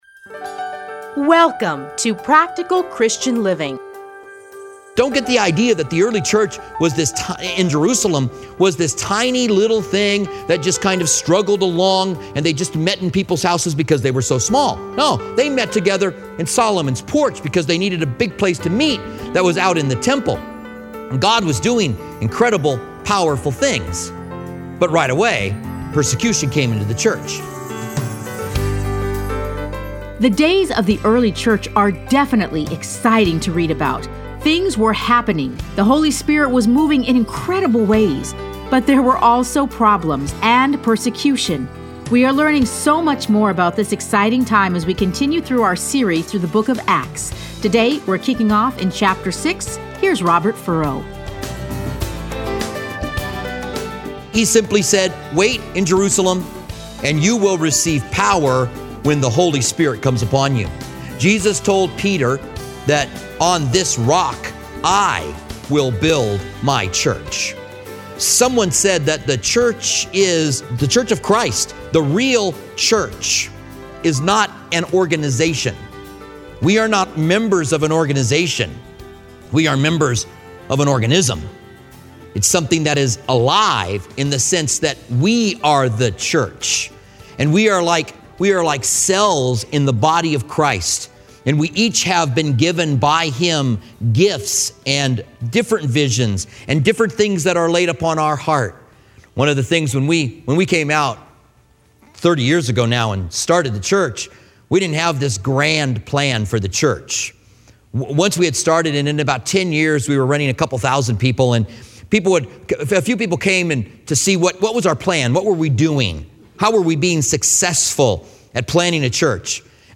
Listen to a teaching from Acts 6-7.